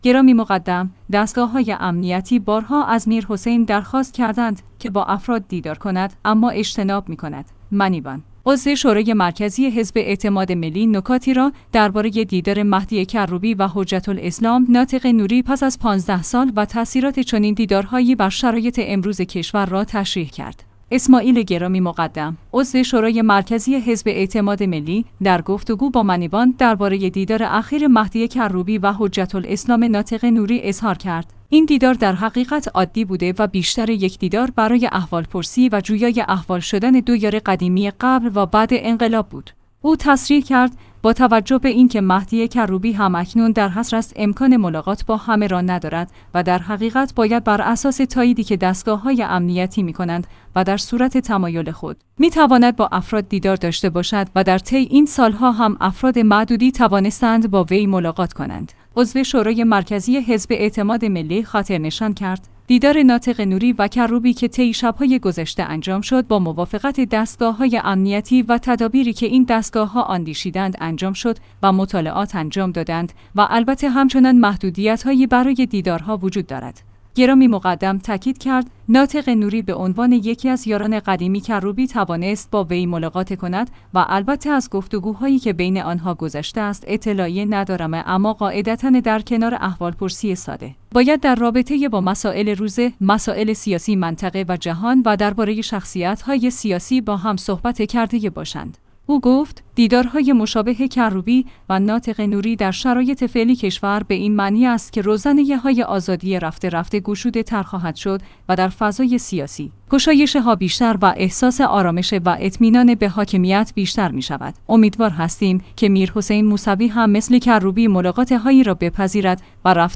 اسماعیل گرامی‌ مقدم، عضو شورای مرکزی حزب اعتماد ملی، در گفتگو با منیبان درباره دیدار اخیر مهدی کروبی و حجت‌الاسلام ناطق نوری اظهار ک